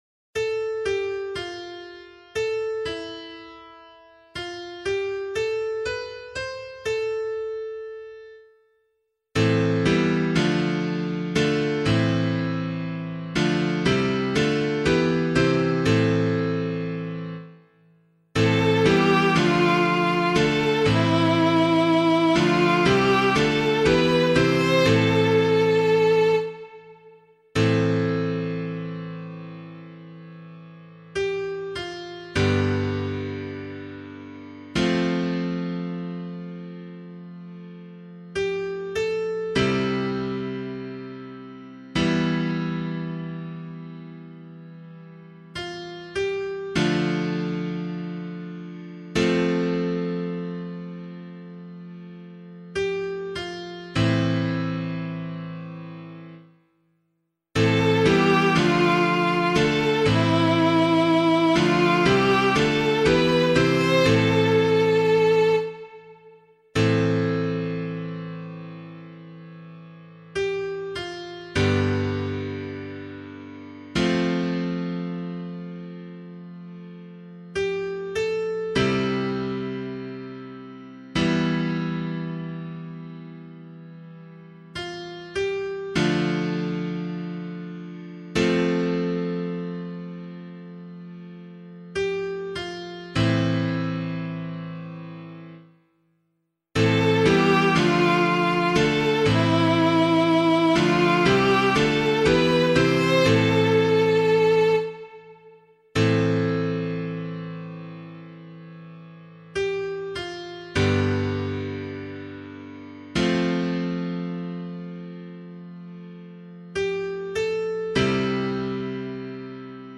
004 Advent 4 Psalm A [LiturgyShare 4 - Oz] - piano.mp3